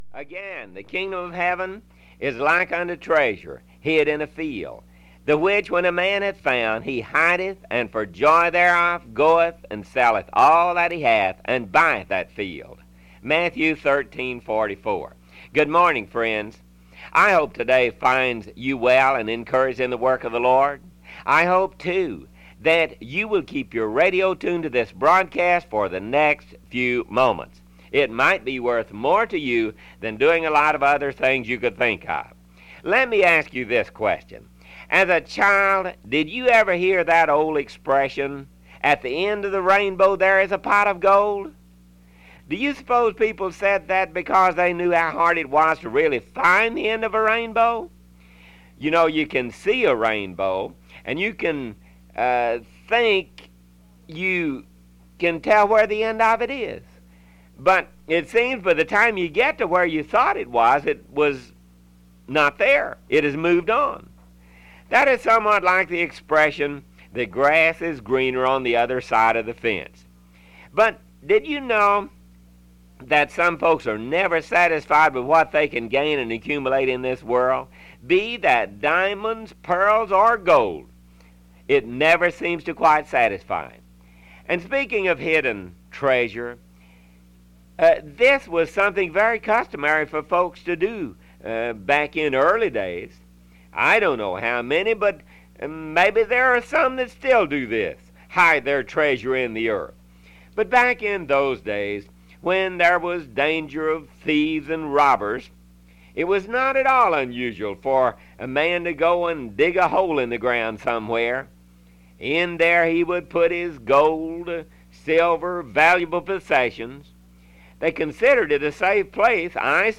The Mission Trail Audio Broadcasts